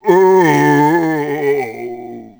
c_zombim3_dead.wav